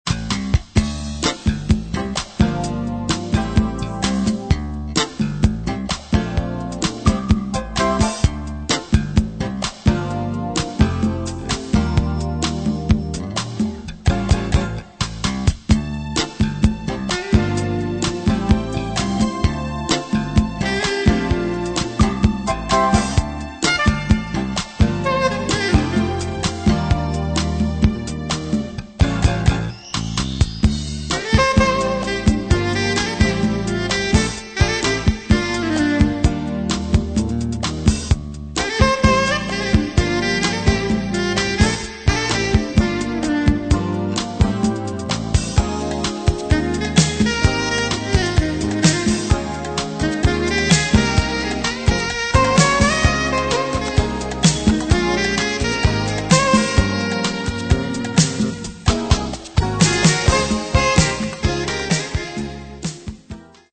GŁADKI JAZZ